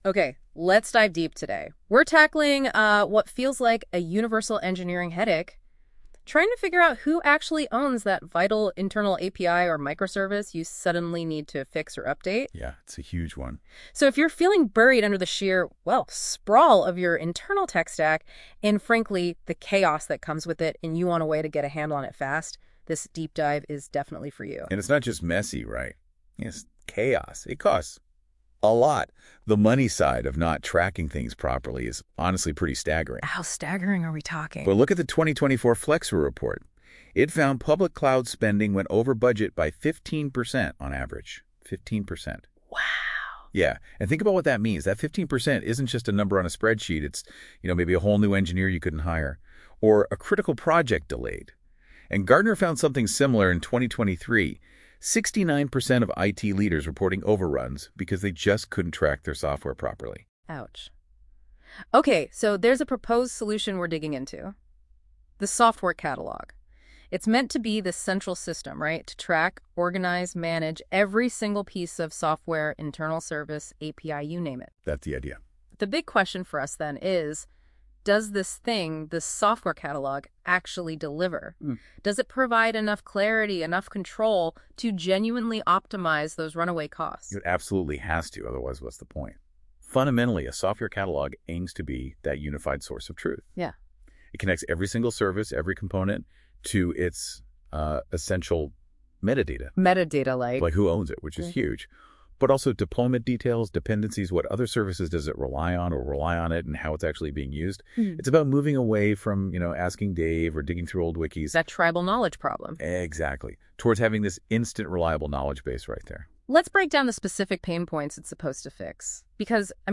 Audio generated by NotebookLM